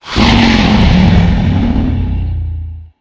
growl4.ogg